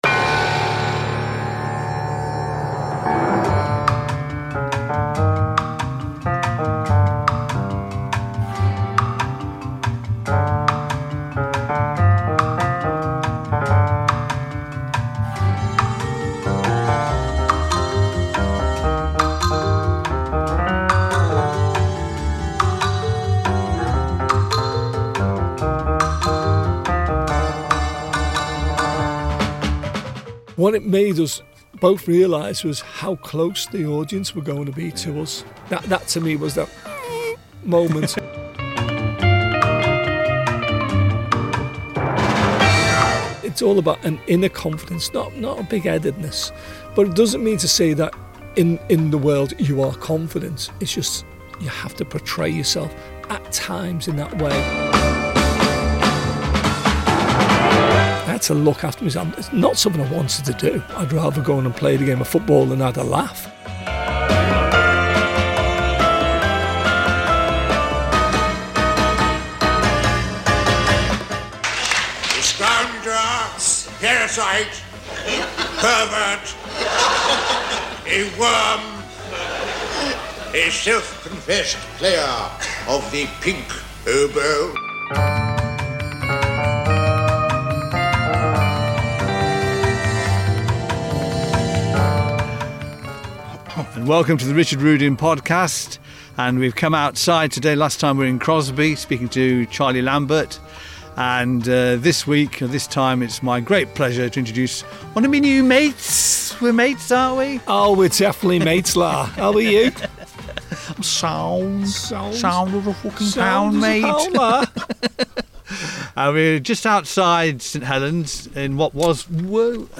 Stand-up comedy
Contains strong language and adult themes.